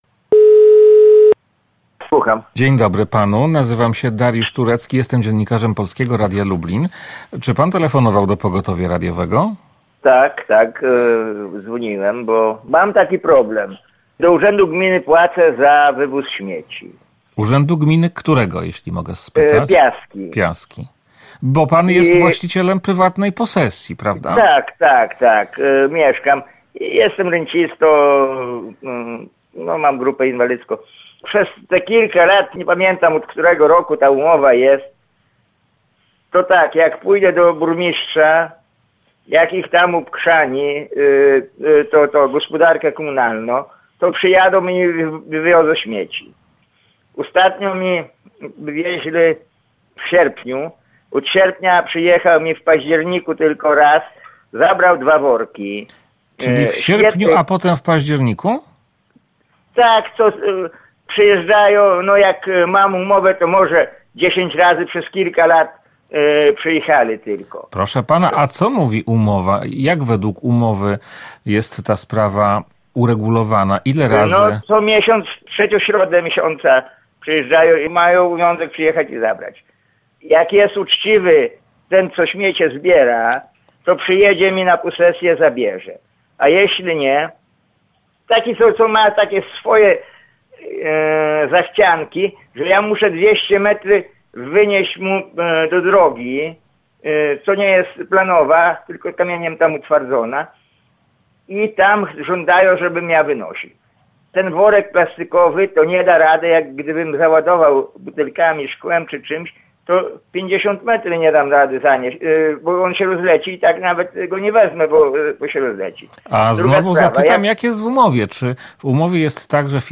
sluchacz_z_gminy_piaski.mp3